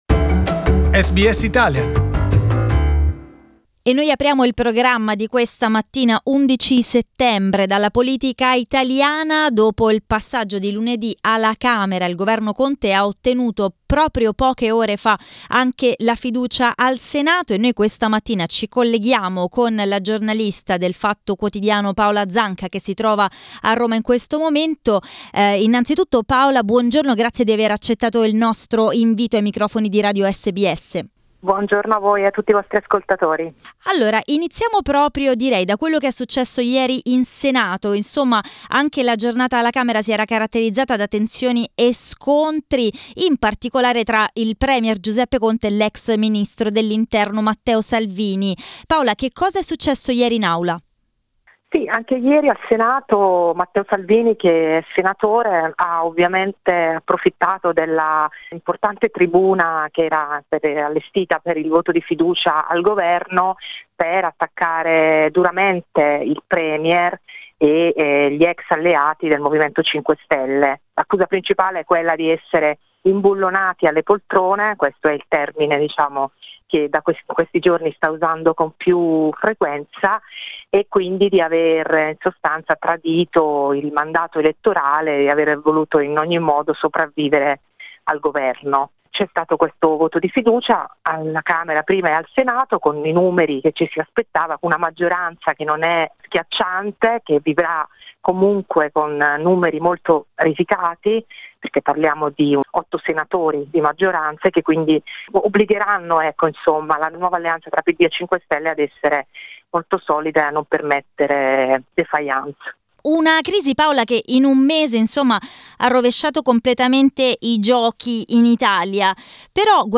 Italian journalist